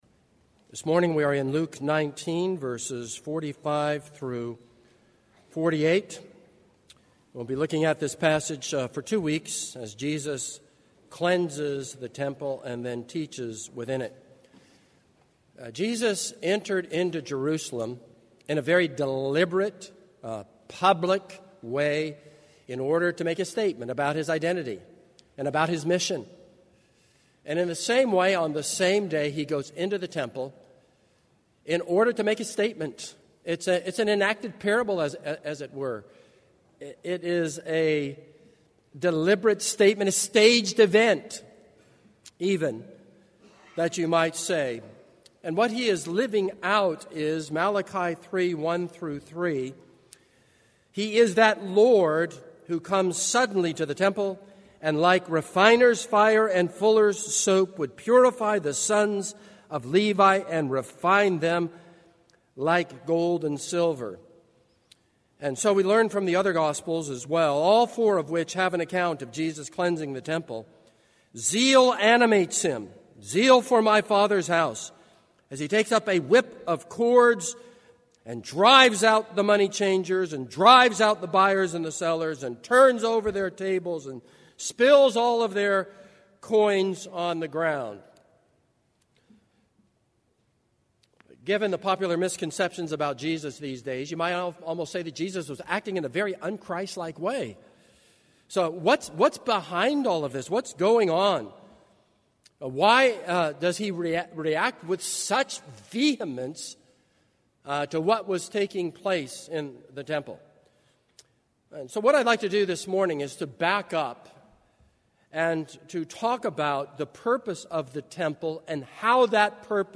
This is a sermon on Luke 19:45-48.